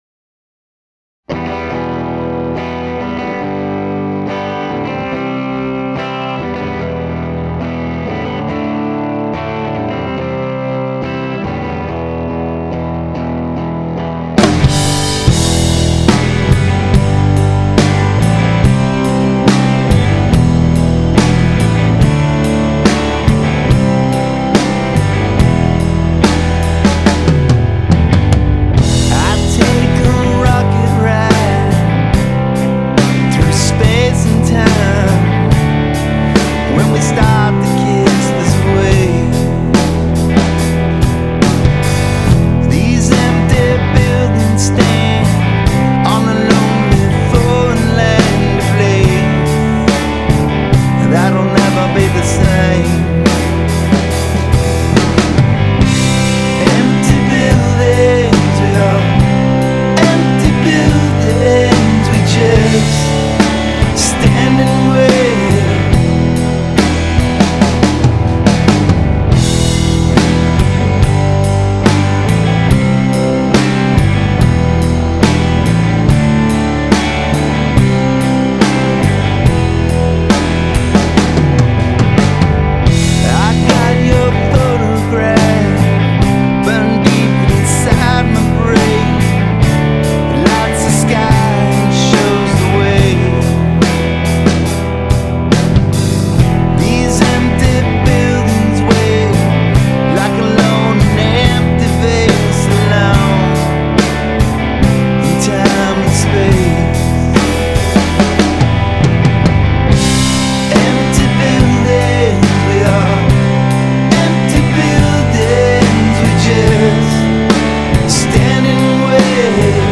End Session Monitor Mix.